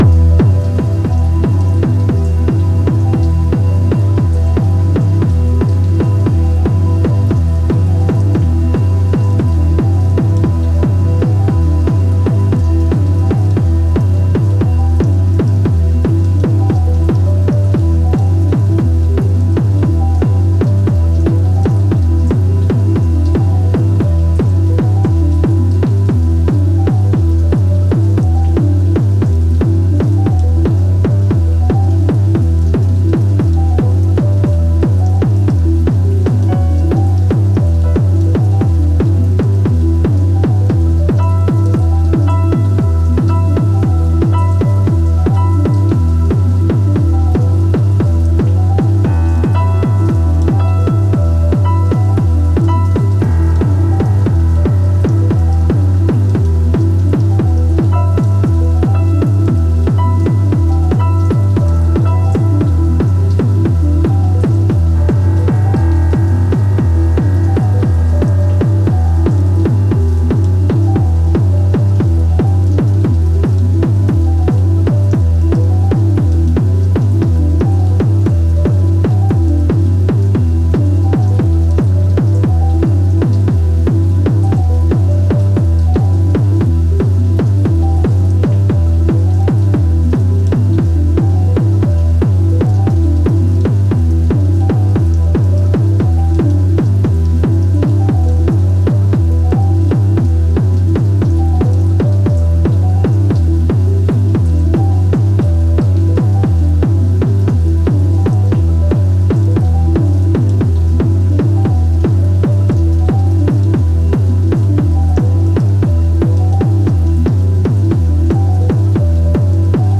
Live Improvisation on Twitch 5-20-23